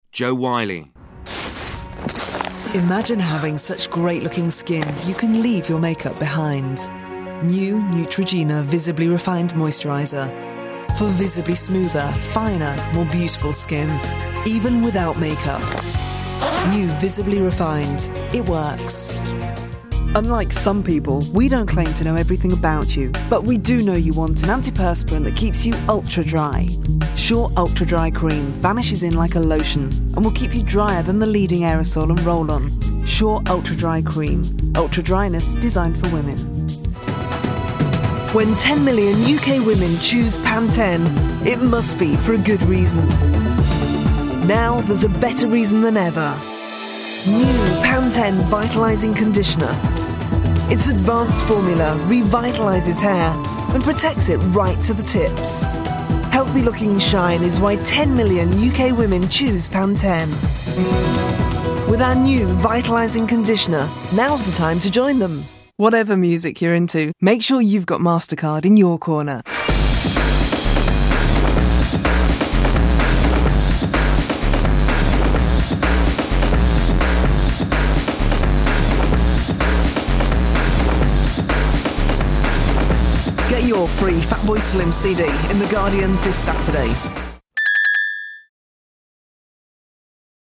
Jo has a distinctively deep and intelligent tone to her voice and is instantly recognisable.  She has a conversational soft sell, is great for musical promos and gives your commercial reads a classy edge.
• Female
• London
• Standard English R P
Jo Whiley – Showreel.
Jo-Whiley-showreel.mp3